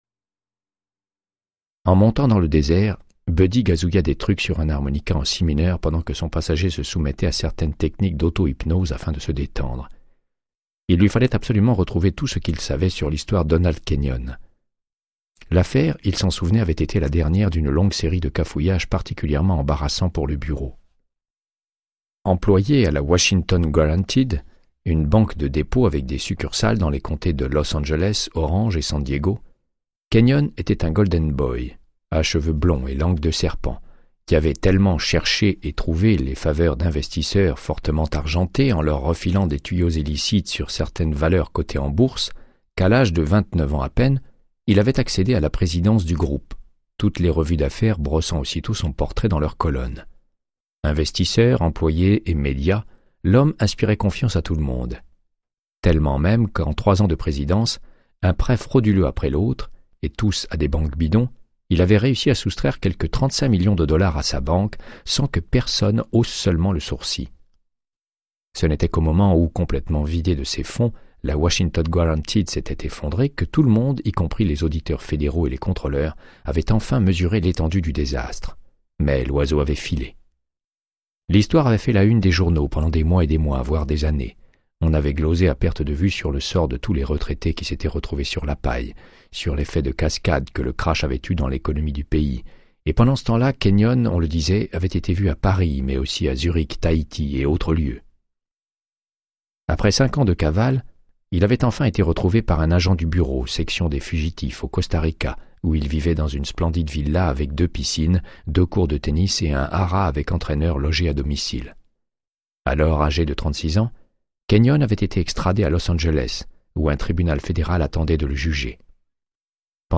Audiobook = Créance de sang, de Michael Connelly - 98